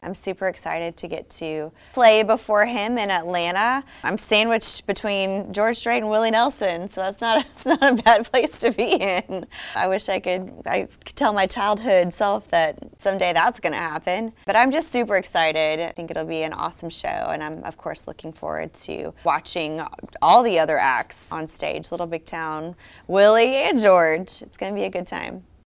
“I’m sandwiched between George Strait and Willie Nelson, so that’s not a bad place to be in,” she laughs.